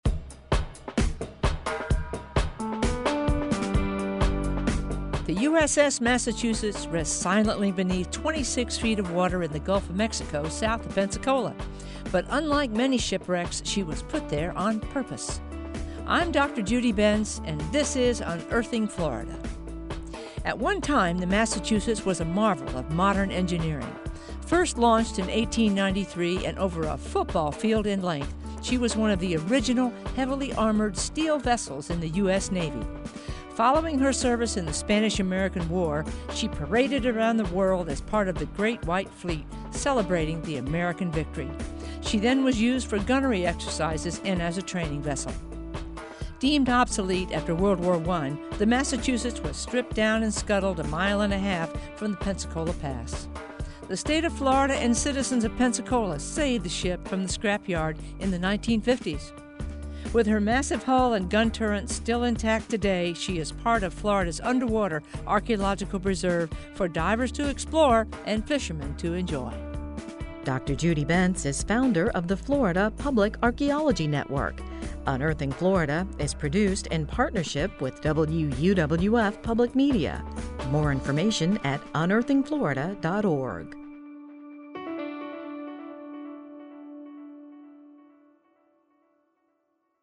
Written, narrated, and produced by the University of West Florida, the Florida Public Archaeology Network and WUWF Public Media.